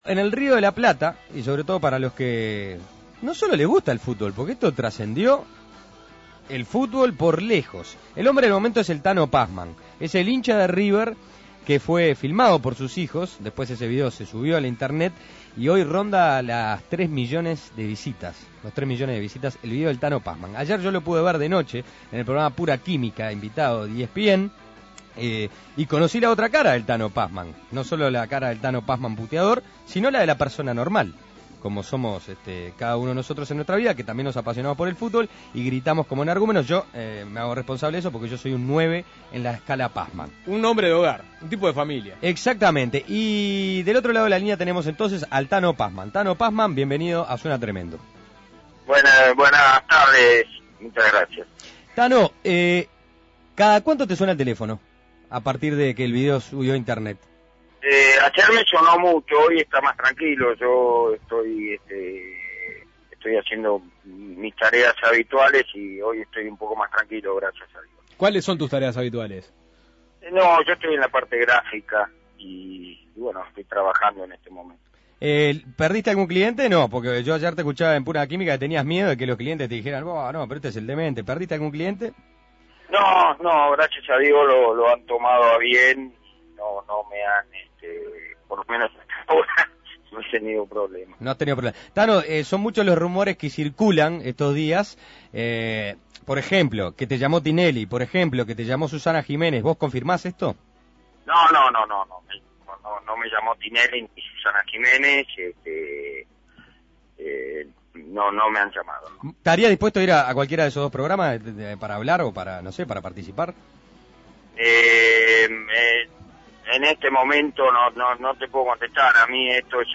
Desde Buenos Aires dialogó con Suena Tremendo.